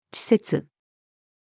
稚拙-female.mp3